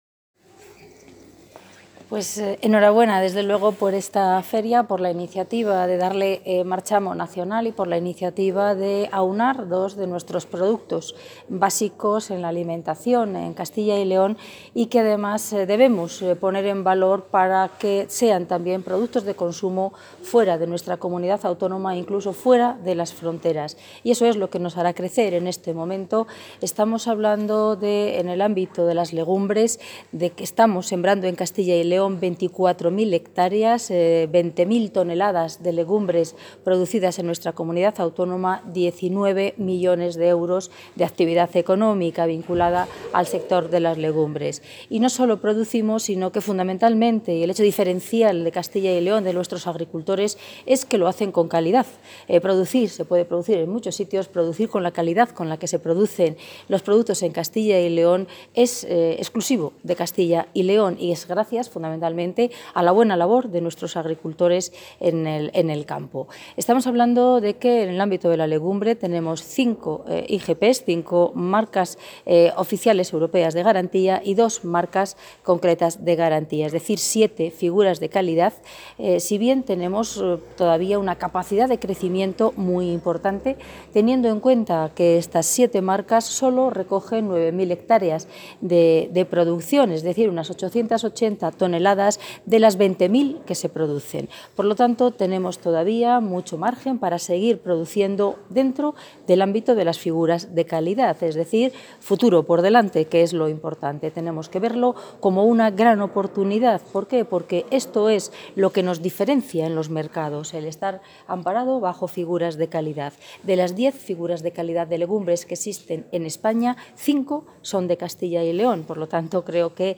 Declaraciones de la consejera de Agricultura y Ganadería.
La consejera de Agricultura y Ganadería y portavoz de la Junta de Castilla y León, Milagros Marcos, ha inaugurado hoy la Feria del Pan y la Lenteja de Tierra de Campos en la localidad vallisoletana de Mayorga, donde, entre otros temas, ha anunciado que la fabiola de Palencia ha obtenido la marca de garantía y que la Asociación Provincial de Fabricantes de Pan recibirá el lunes el reglamento de uso de este distintivo de calidad.